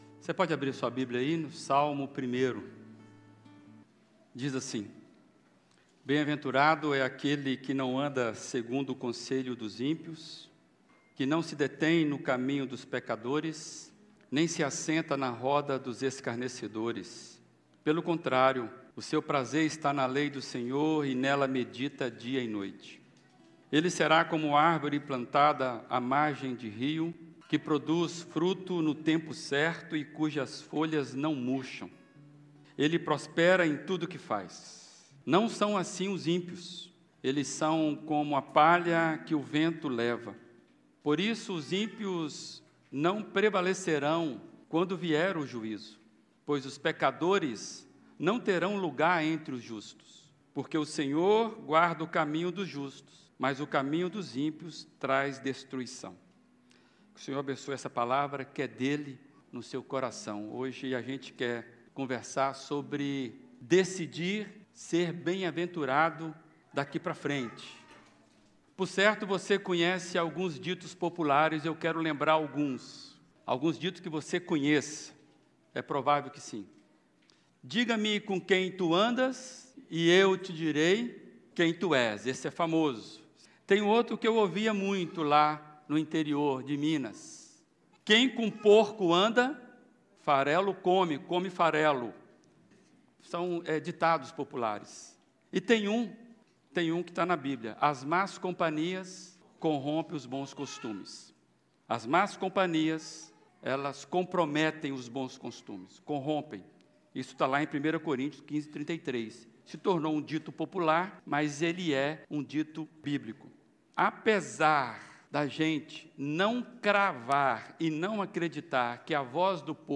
na Primeira Igreja Batista de Brusque